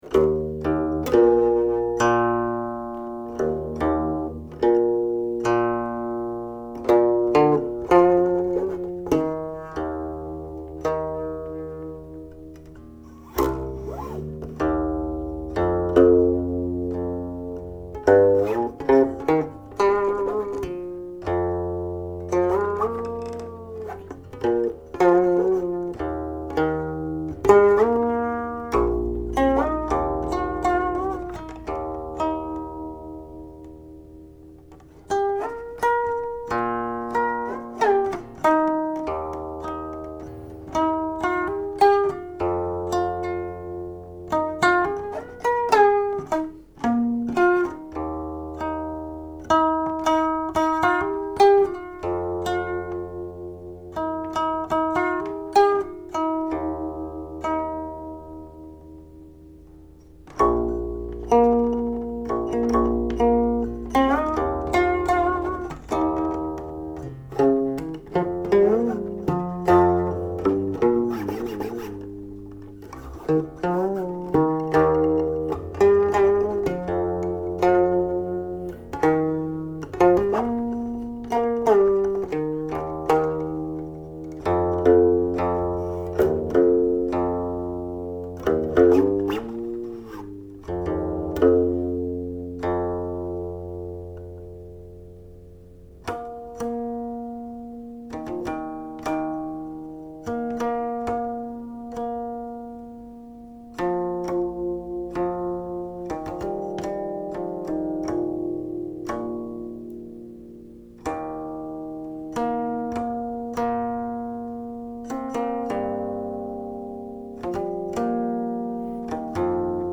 7 sections, untitled; Section 6 begins with a setting of the lyrics translated above (Chinese) )
01.35   4. (harmonics)
04.16         Closing harmonics